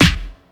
• Fresh Hip-Hop Steel Snare Drum Sound E Key 66.wav
Royality free steel snare drum sound tuned to the E note. Loudest frequency: 1705Hz
fresh-hip-hop-steel-snare-drum-sound-e-key-66-dwc.wav